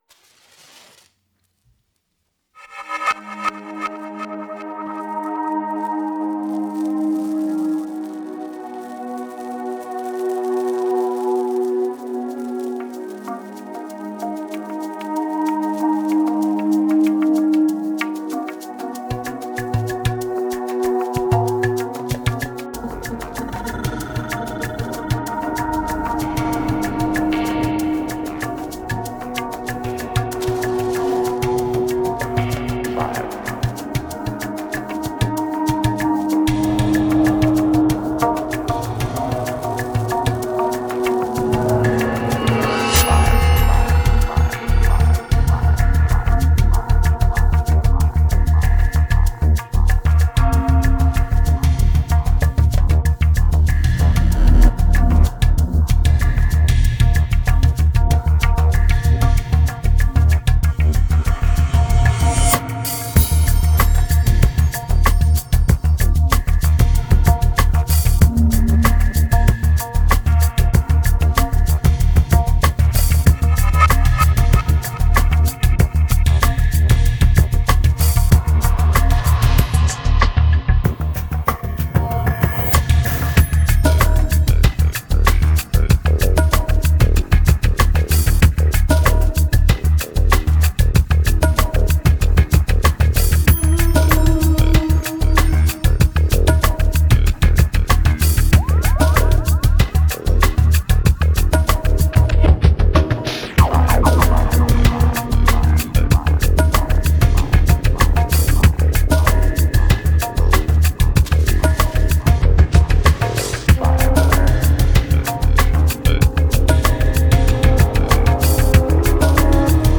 Genre: Downtempo, Chillout, World.